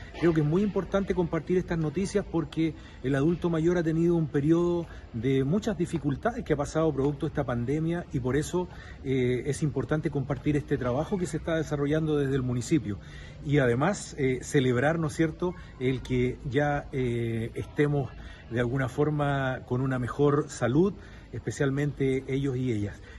El alcalde, Armando Flores, señaló que “compartimos con ellos varias buenas noticias, que tienen que ver con el mejoramiento de calles, un proyecto gigantesco que tenemos como municipio que va a comenzar este año 2022” agregando que se informó en la ocasión sobre otros proyectos, como reposición de plazas y de otros espacios públicos.